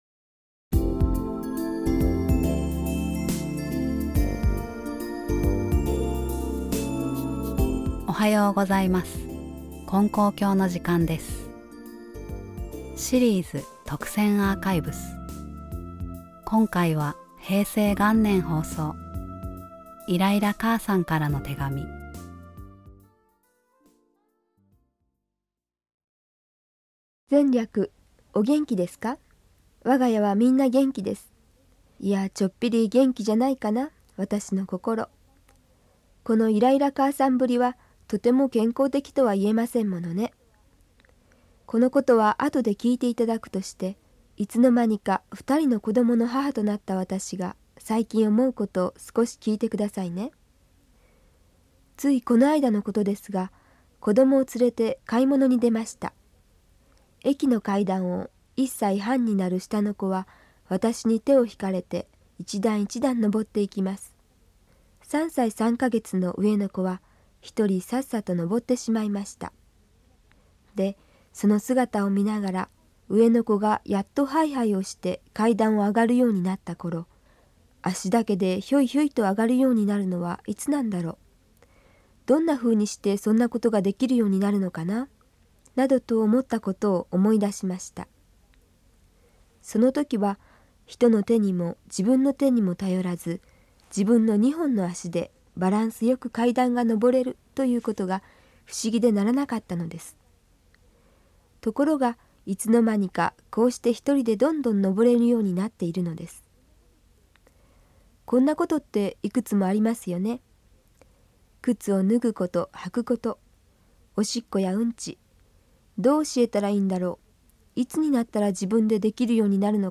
（平成元年３月29日放送）